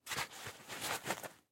Звуки лифчика